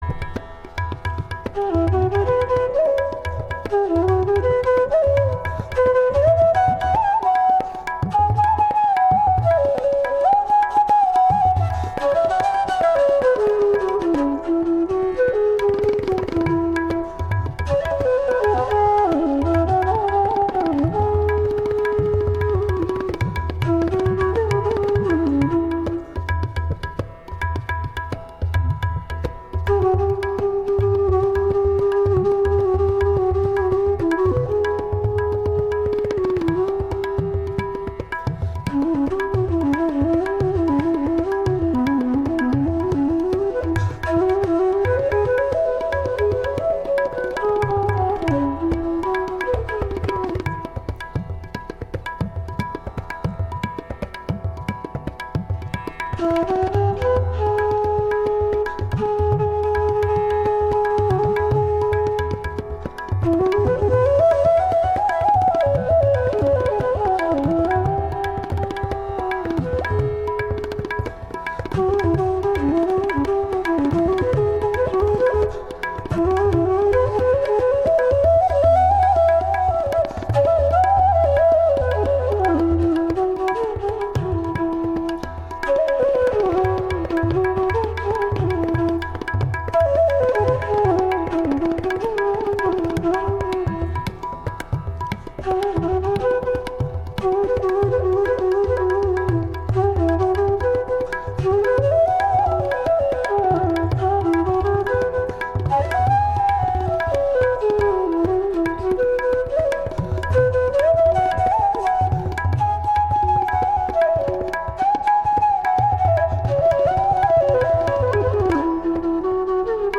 Genre: North Indian Classical.
Dhun: Keherwa Tal (8)   10:01